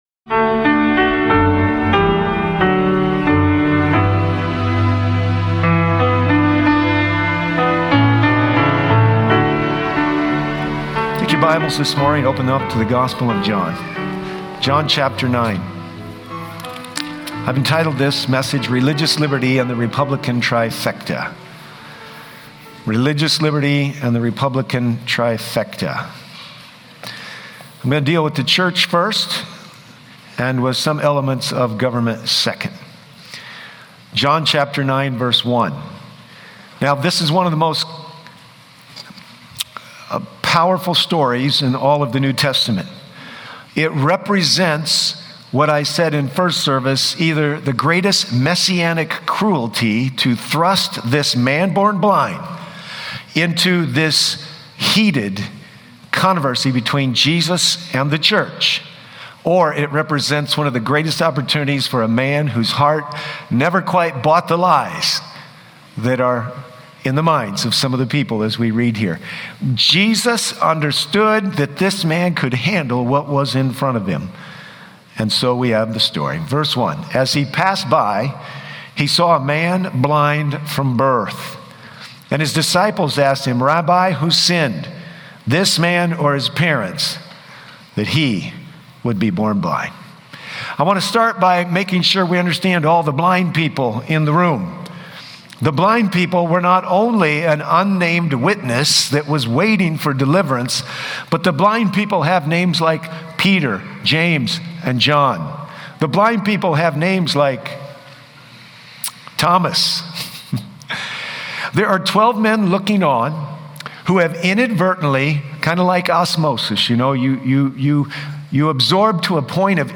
Spiritual blindness isn’t just a problem of the past—it’s a challenge for today’s believers, calling us to move beyond tradition and into true discipleship. This sermon explores the urgency of working for Christ, the church’s role in societal issues, and how faith intersects with politics, ultimately urging believers to be bold in their testimony and stand for truth in a shifting world.